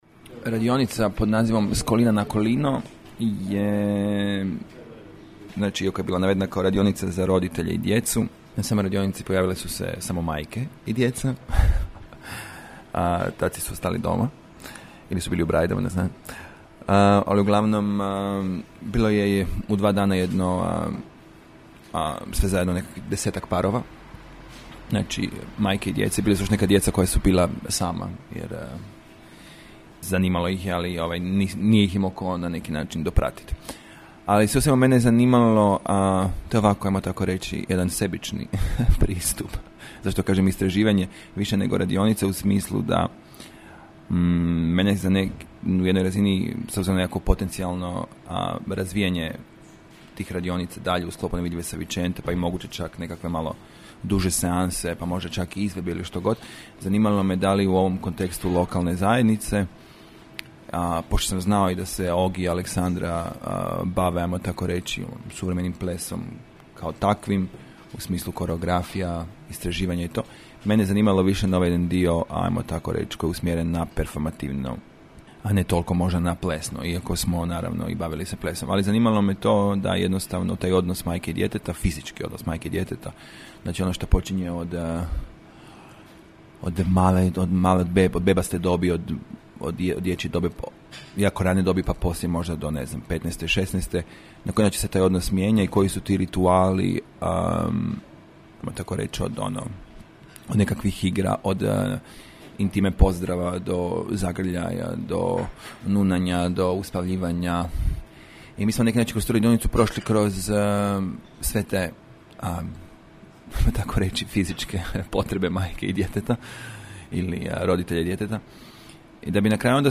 Razgovor o radionici